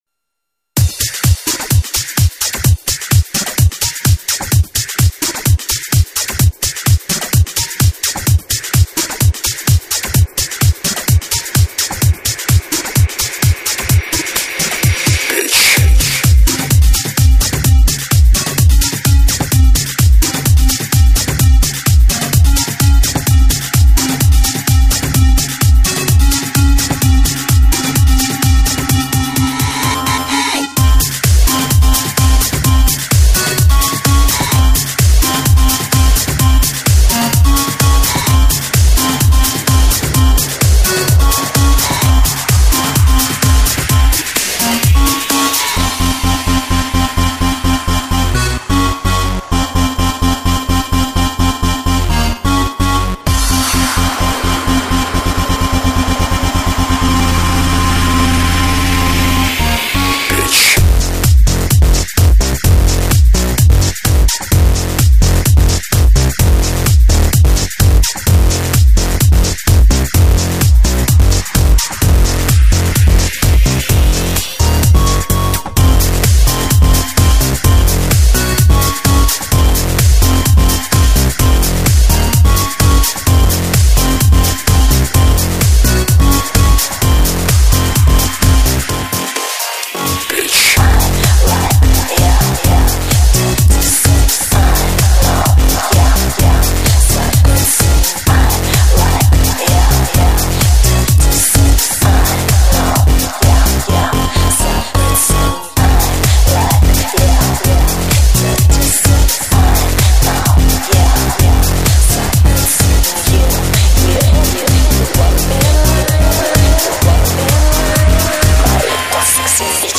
Жанр:Новогодний/Позитивный/Electro/House